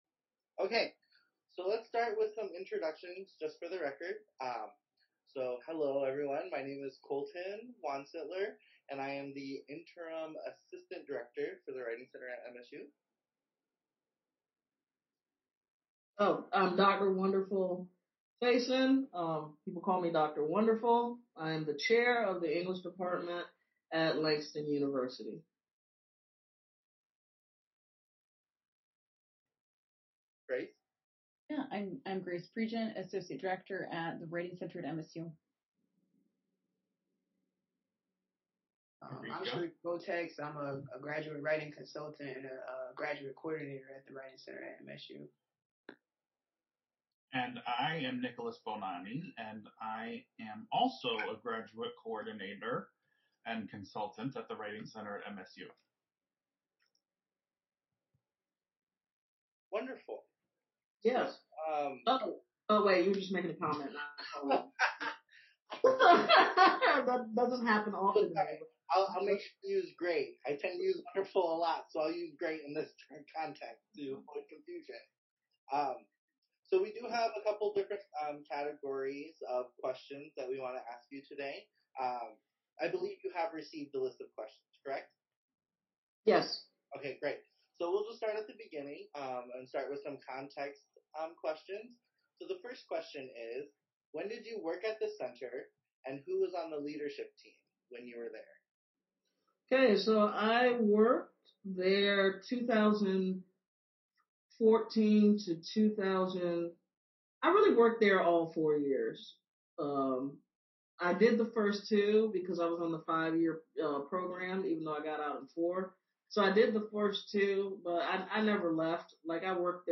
The Writing Center at Michigan State University’s Oral History Research Project